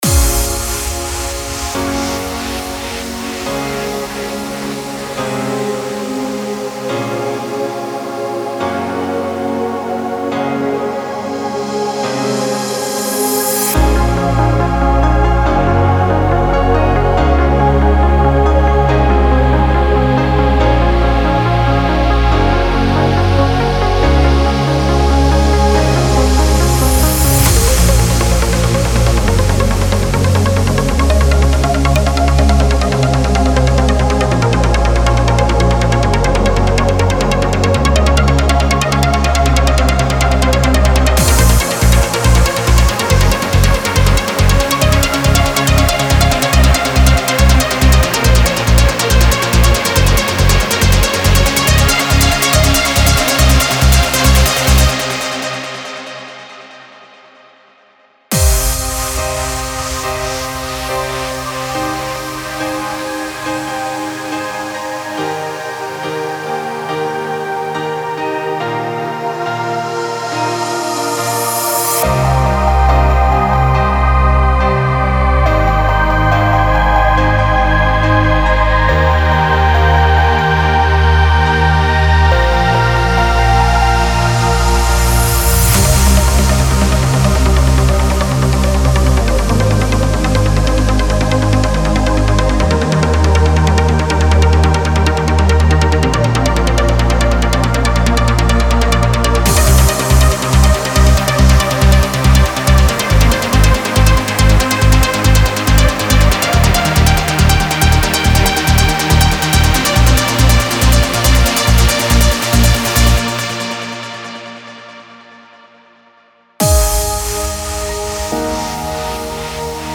1 x Spire Presets (Ambient Voice Pad) [Version 1.5 or high]
Preview demo is 140 BPM.
Style: Trance, Uplifting Trance